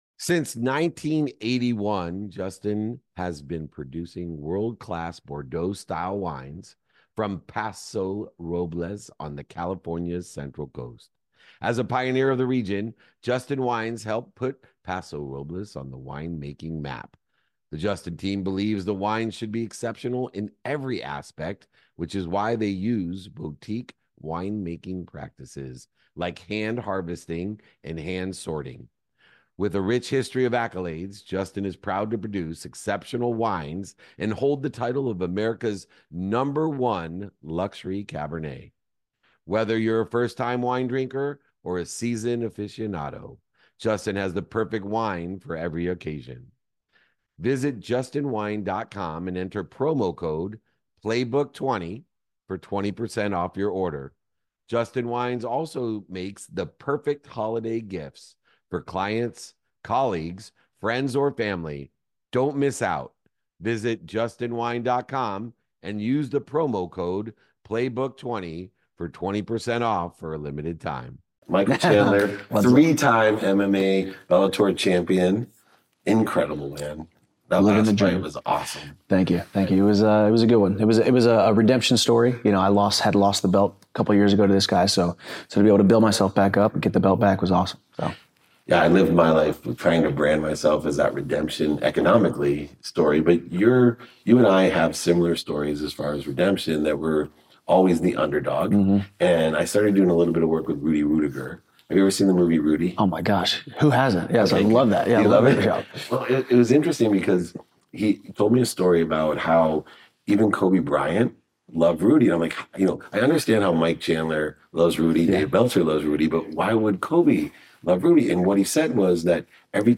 In today’s episode, I sit down with Michael Chandler, a three-time MMA world champion known for his relentless drive and redemption story.